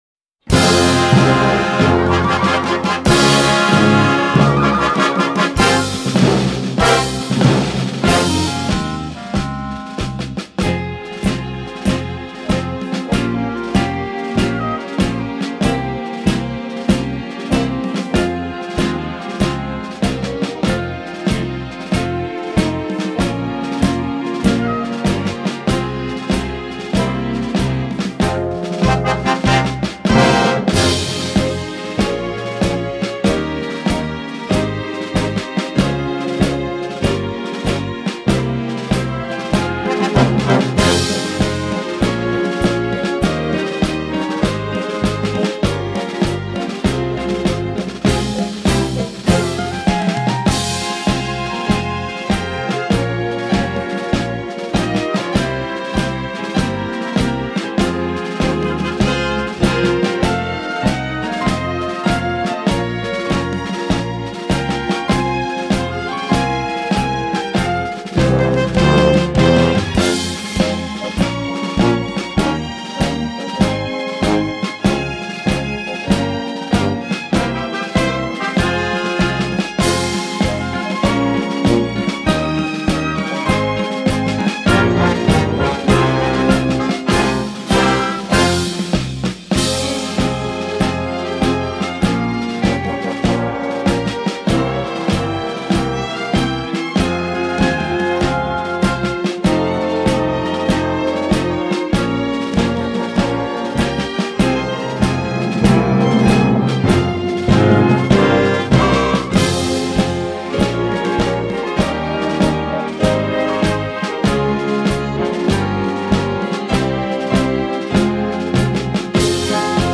инструментальная версия